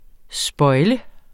Udtale [ ˈsbʌjlə ]